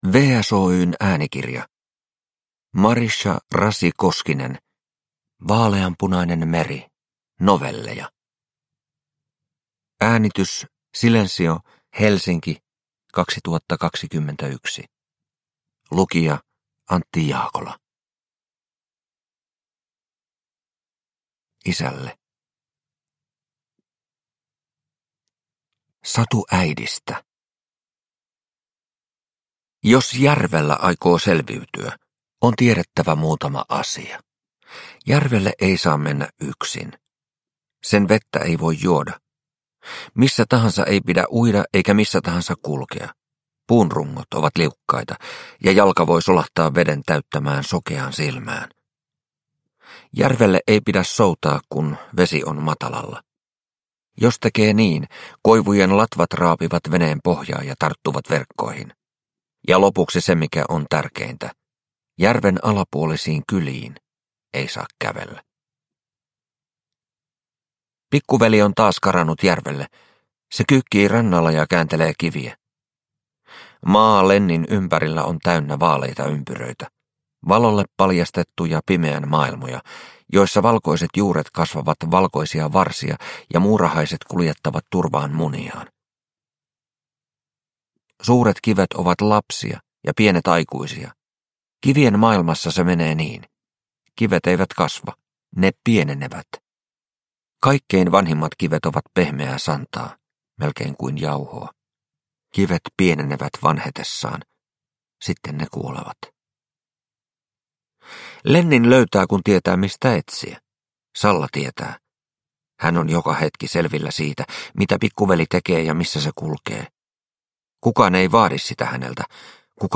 Vaaleanpunainen meri – Ljudbok – Laddas ner